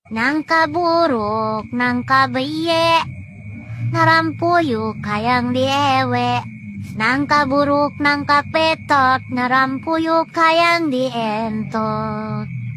nangka buruk tamamo cross Meme Sound Effect
Category: Anime Soundboard